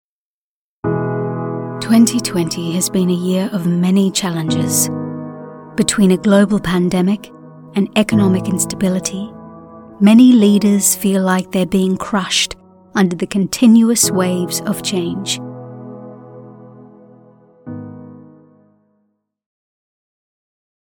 Female
Political Spots
British Sullen Serious Sad